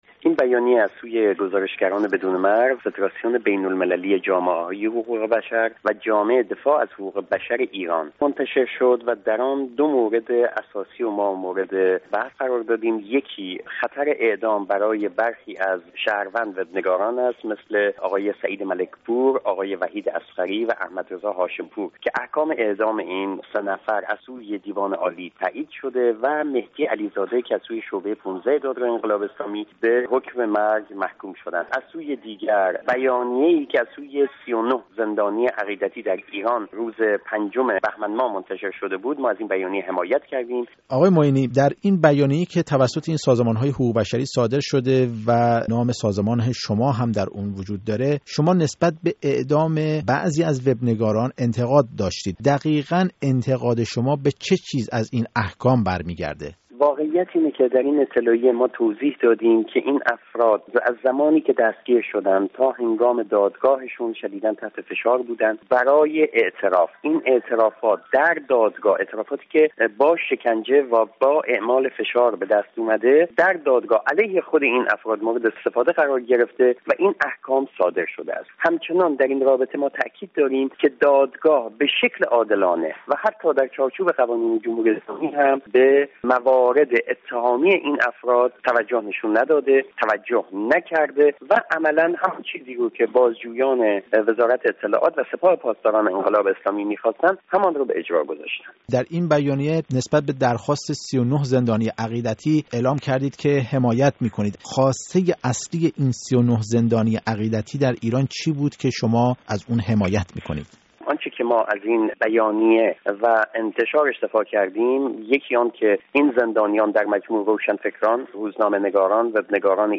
گفت‌و‌گوی